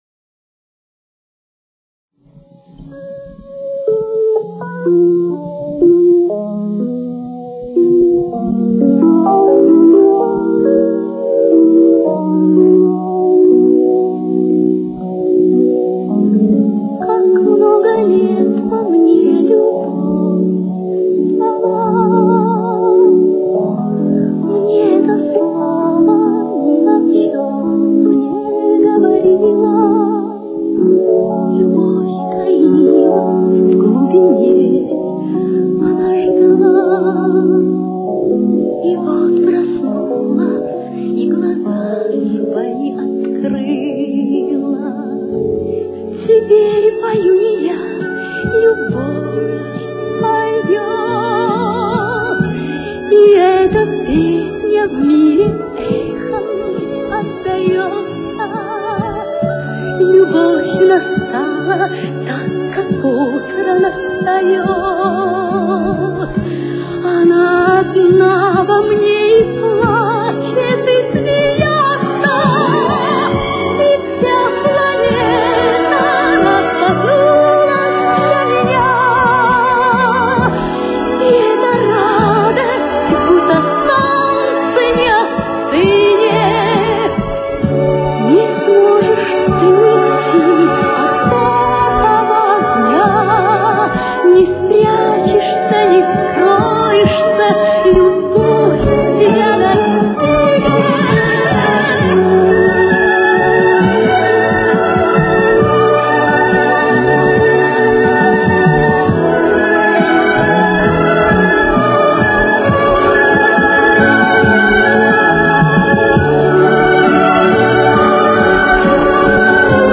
с очень низким качеством (16 – 32 кБит/с)
Темп: 71.